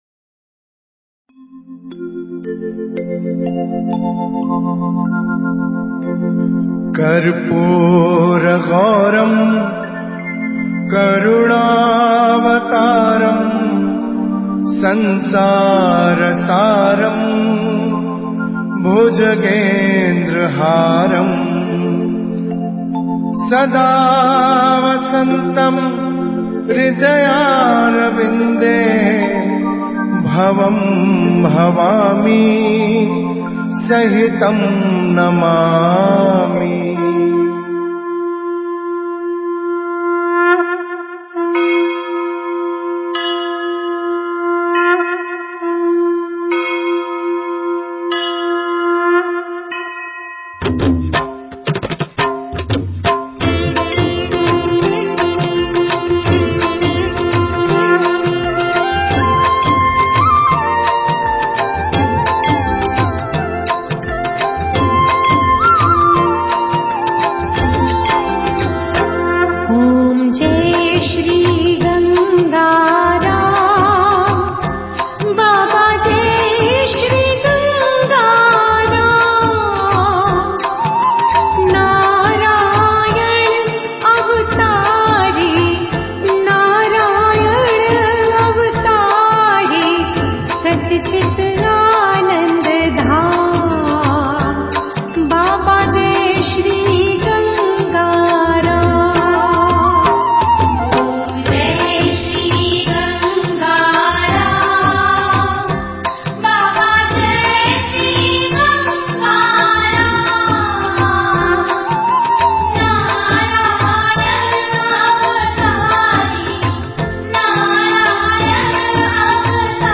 03 gangaram aarti.mp3